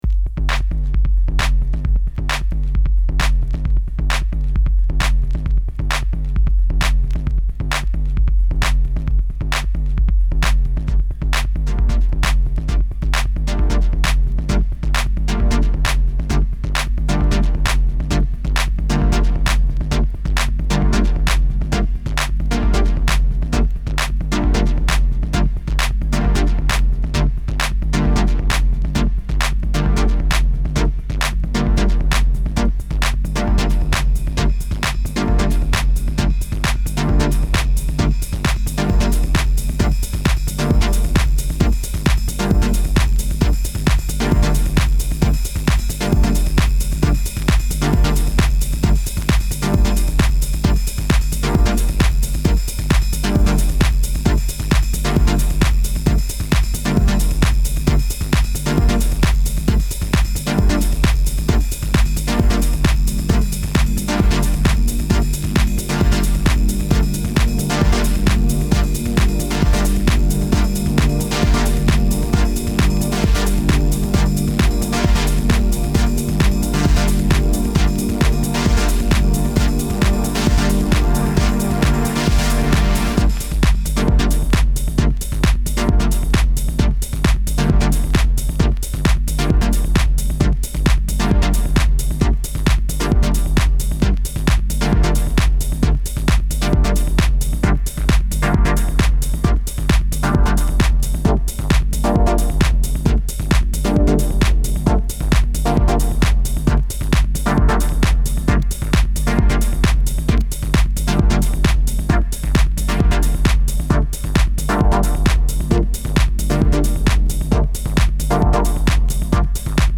Category: Up Tempo